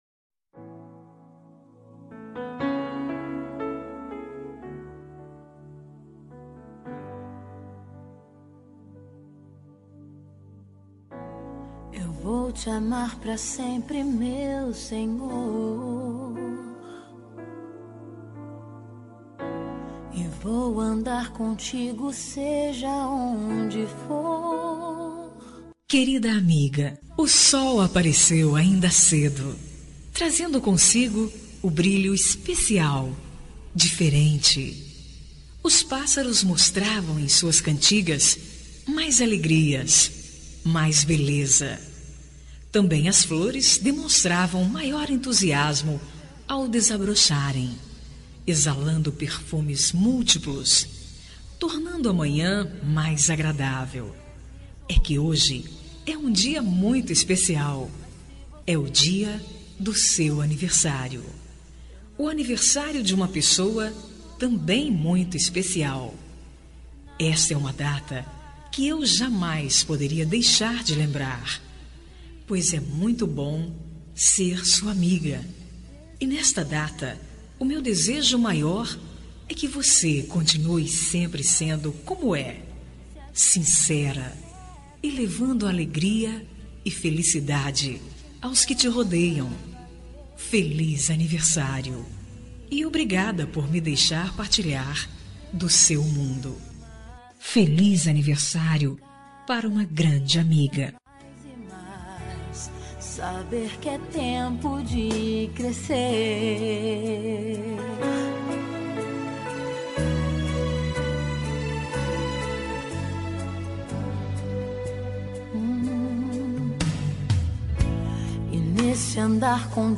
Aniversário de Amiga Gospel – Voz Feminina – Cód: 6200 – Linda
6200-amiga-gospel-fem.m4a